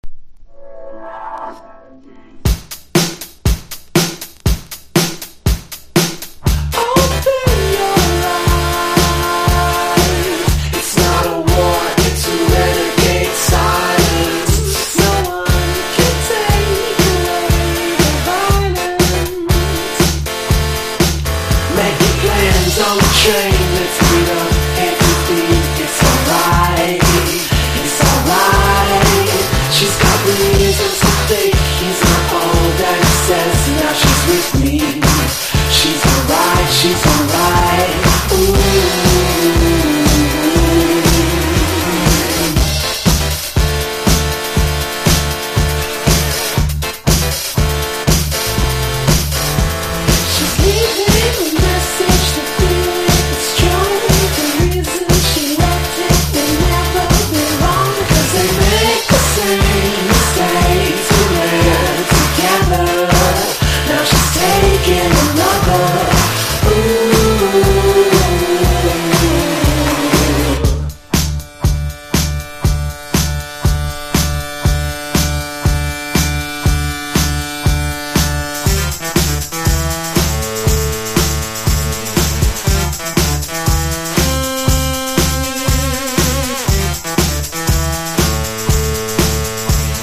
1. 00S ROCK >
エレクトロの向こう側を見据えたソウルフルなシンセ・ポップサウンド！！